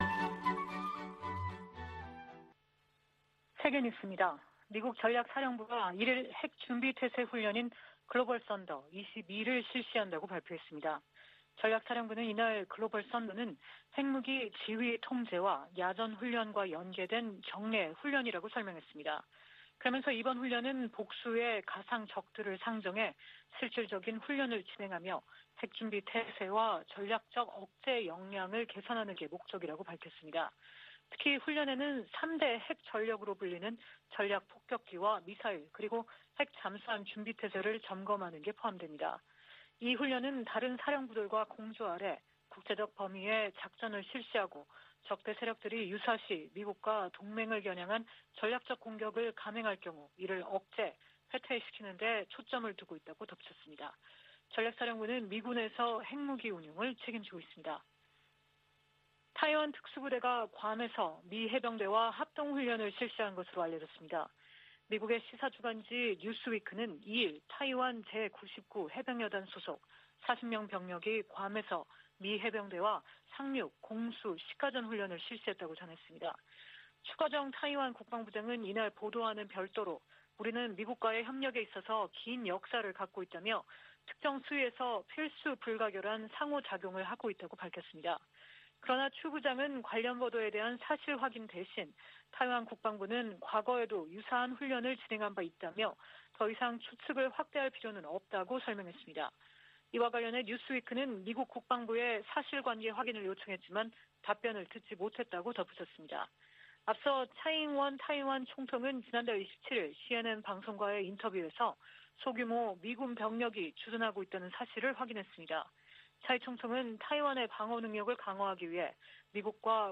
VOA 한국어 아침 뉴스 프로그램 '워싱턴 뉴스 광장' 2021년 11월 3일 방송입니다. 중국과 러시아가 유엔 안전보장이사회에 대북제재 완화를 위한 결의안 초안을 다시 제출했습니다.